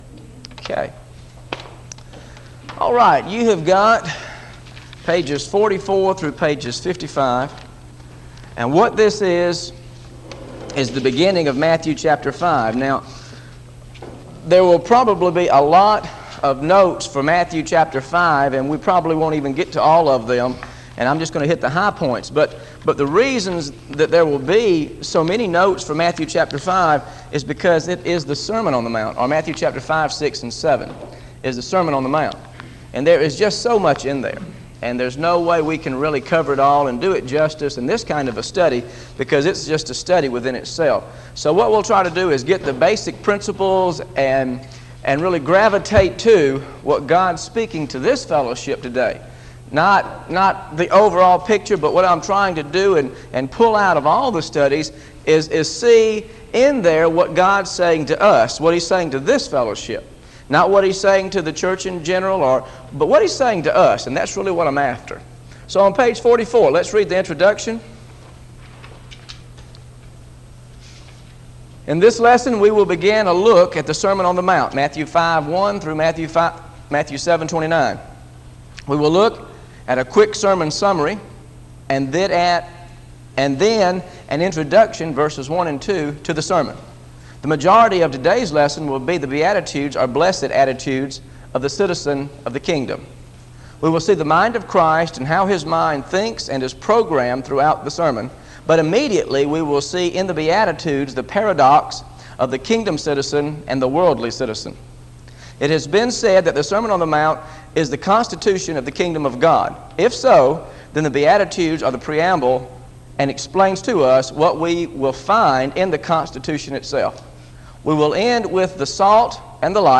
Listen to Matthew 5 Part 1 Teaching